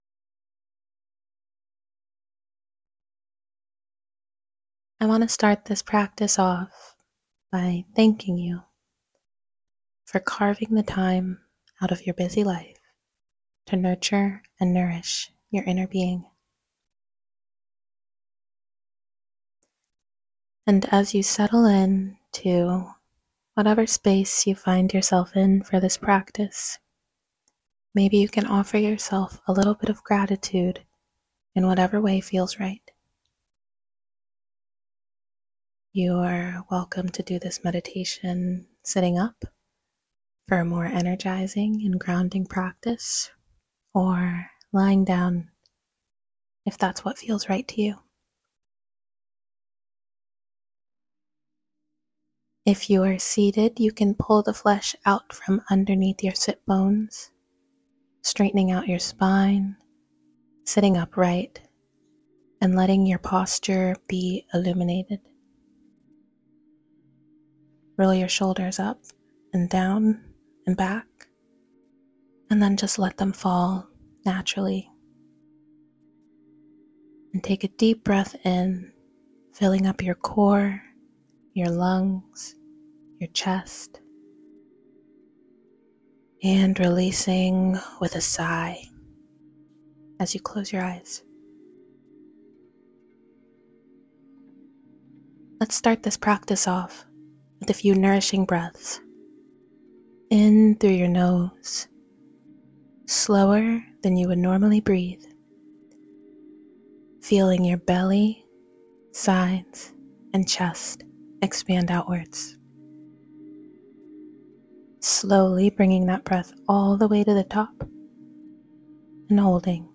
Finalized-Micro-Meditation-with-music.mp3